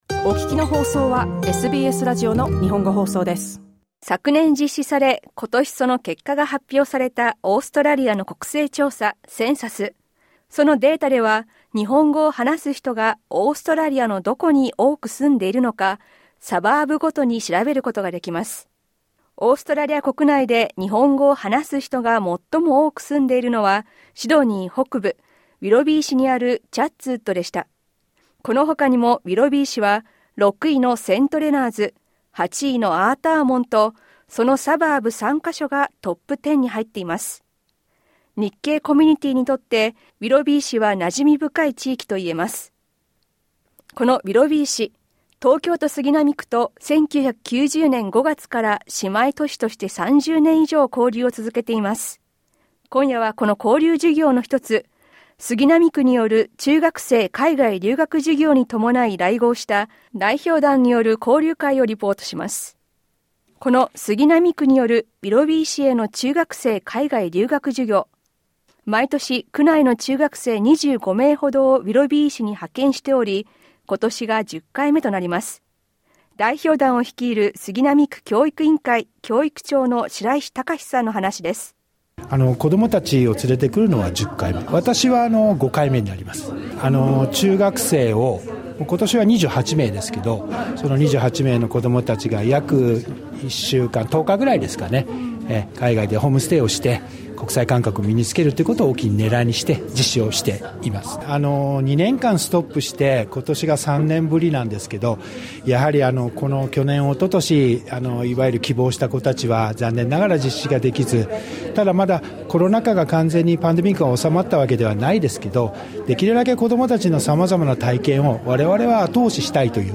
区の代表団に話を聞いた詳しい音声リポートは、再生ボタンをクリックしてどうぞ。